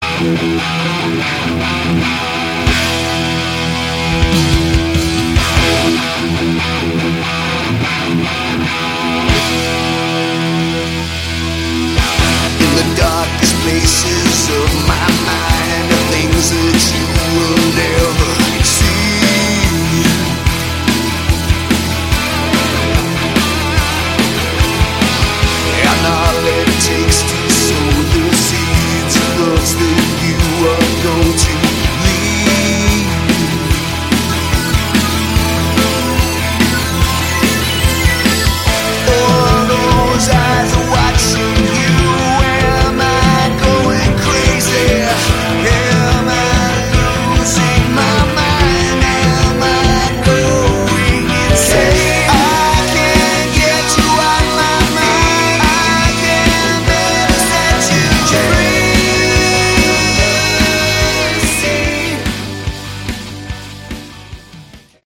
Category: Hard Rock
guitars
drums
keyboards
Instrumental